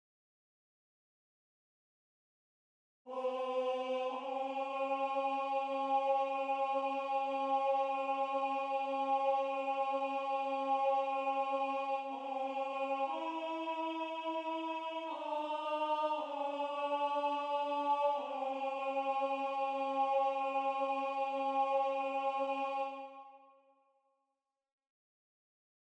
Key written in: C# Major
Type: Barbershop